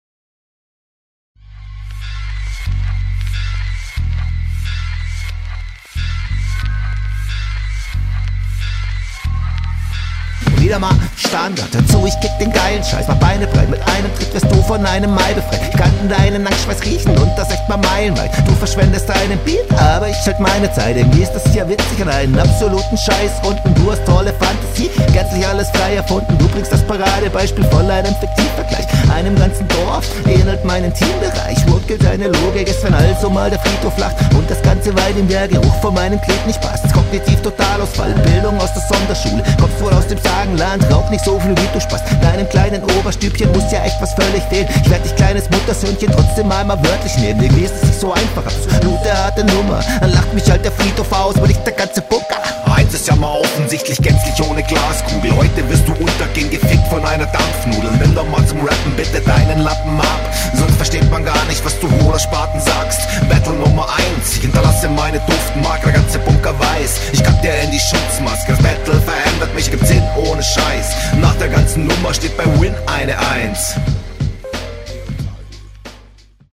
schonmal interessanter auf dem beat, aber hin und wieder auch bisi übern takt gestolpert. dein …